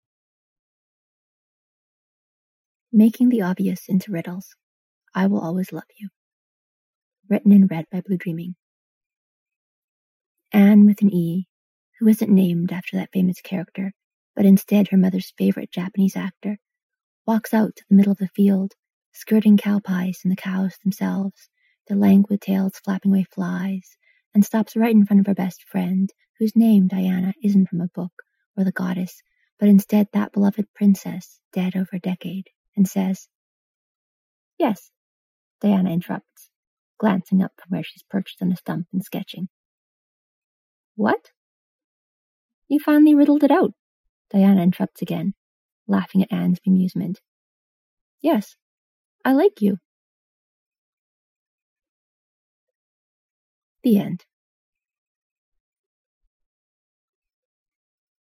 Podficcer's notes: Got a new phone, so I had to try that out instead of my regular mic :D